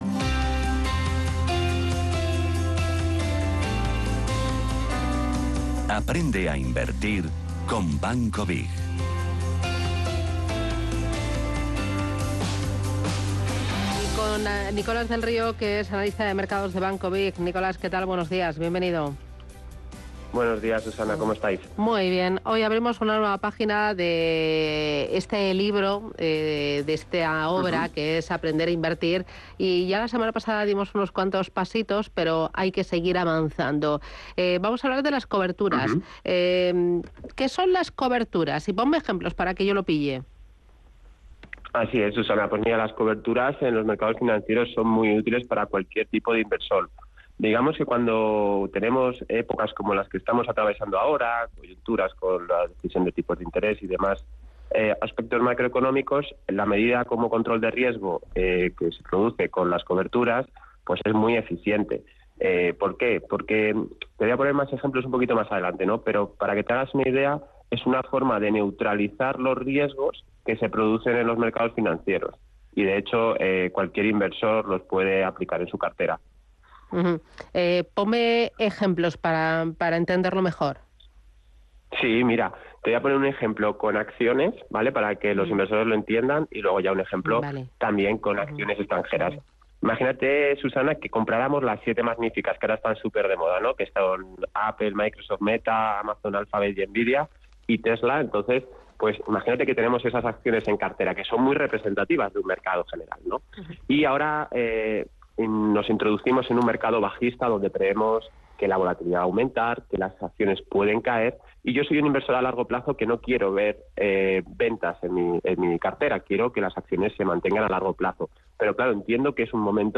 Sección "Aprende a Invertir con BiG" junto a Radio Intereconomía